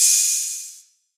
DDW2 OPEN HAT 1.wav